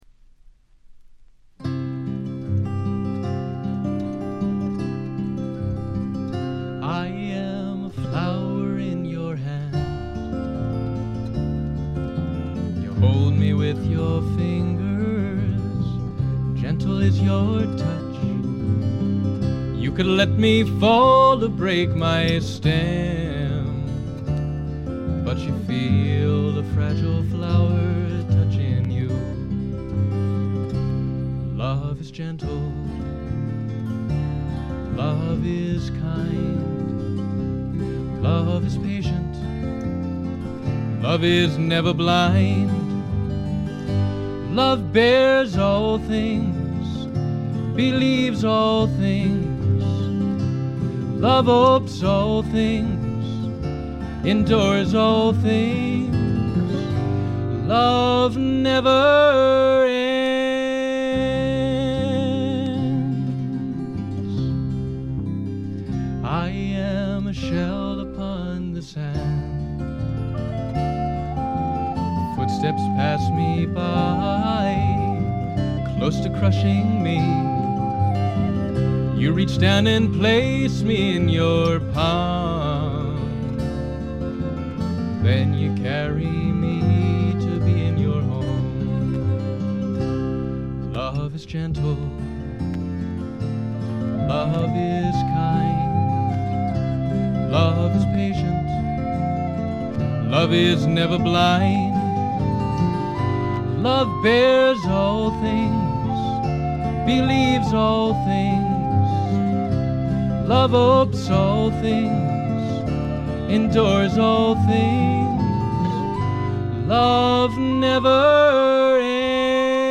全編を通じて見事にサイレントで聖なる世界が展開します。
ずばりドリーミーフォークの名作と言って良いでしょう。
試聴曲は現品からの取り込み音源です。